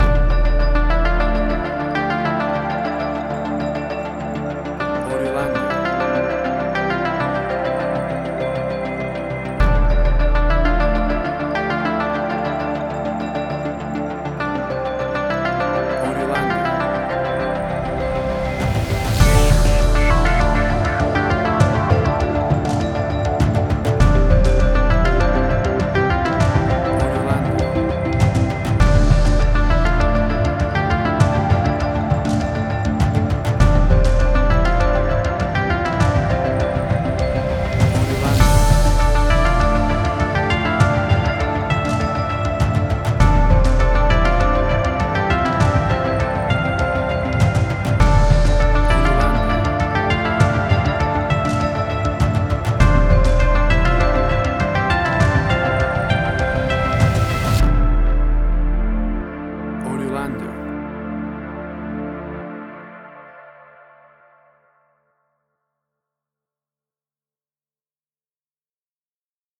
Big impact suspense reality TV style tension music.
Tempo (BPM): 101